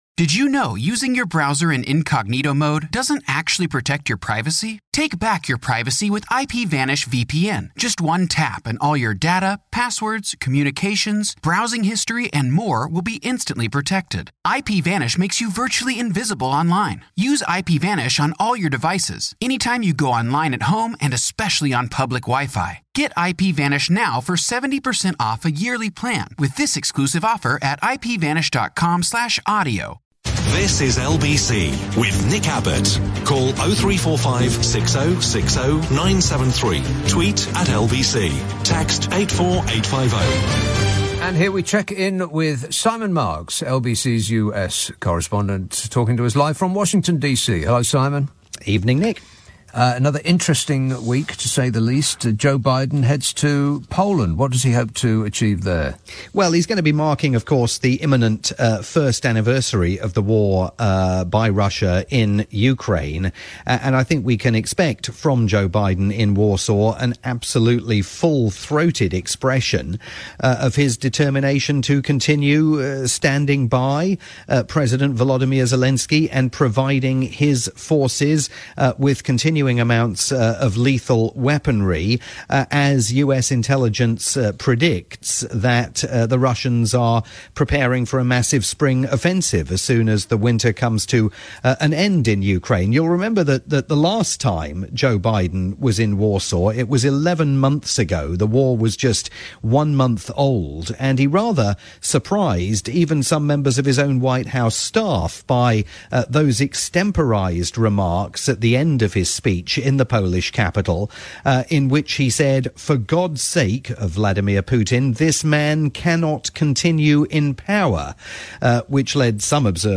Sunday night / Monday morning programme on LBC. Listen live most Mondays at 1230am or find it on-demand here afterwards.